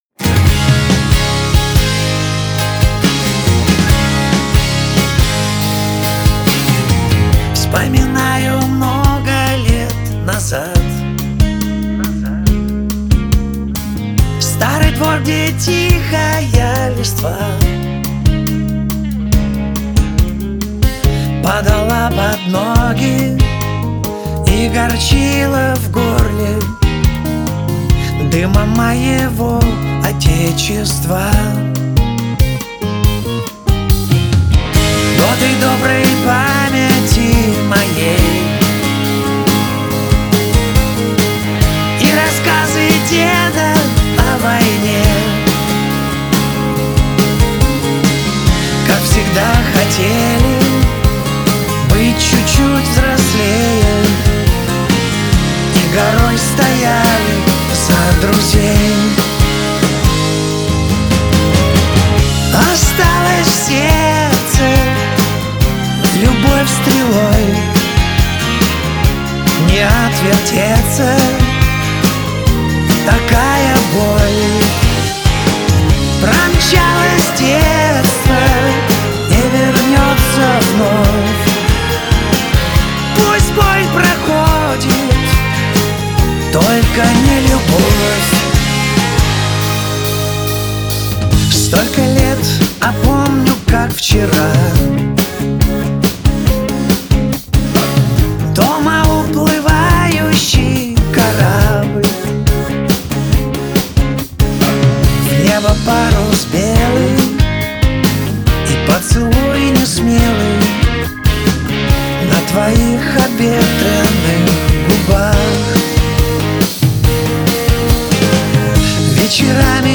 выполненная в жанре поп-рок.